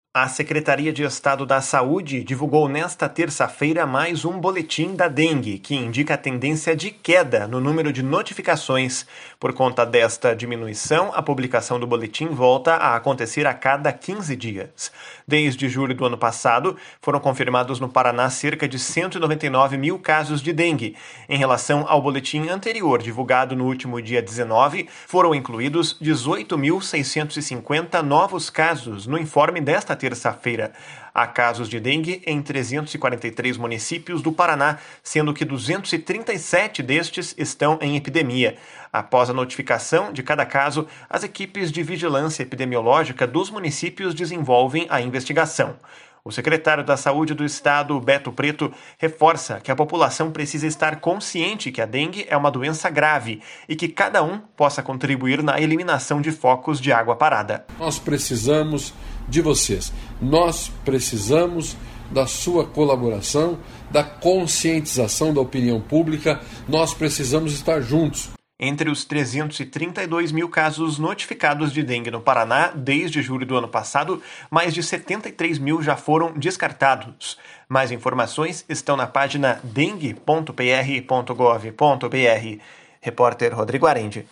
O secretário da Saúde do Estado, Beto Preto, reforça que a população precisa estar consciente que a dengue é uma doença grave, e que cada um possa contribuir na eliminação de focos de água parada. // SONORA BETO PRETO //